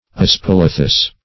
aspalathus - definition of aspalathus - synonyms, pronunciation, spelling from Free Dictionary
Aspalathus \As*pal"a*thus\, n. [L. aspalathus, Gr.